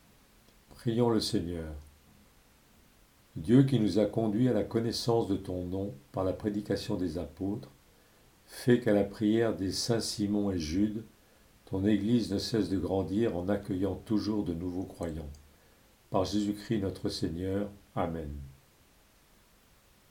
Saints Simon et Jude - Chorale Paroissiale du Pôle Missionnaire de Fontainebleau
Antienne invitatoire